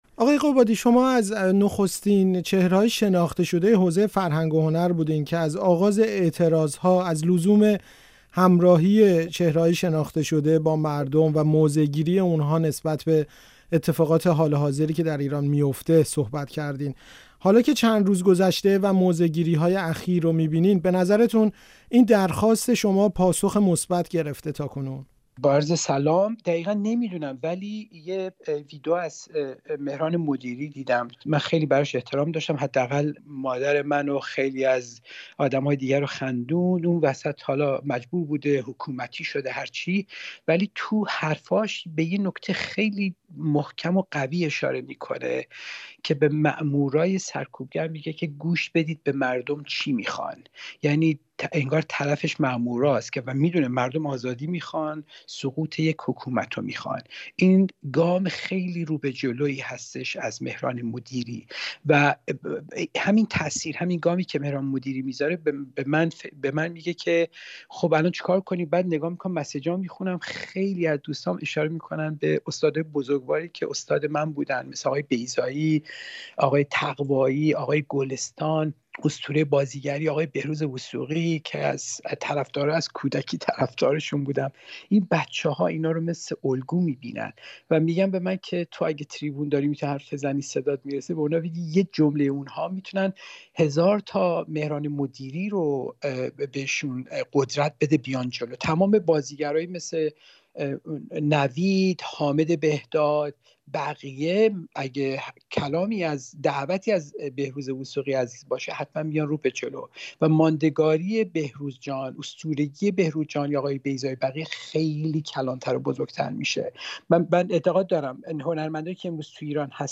آرزو داشتم جای ژینا باشم؛ گفت‌وگو با بهمن قبادی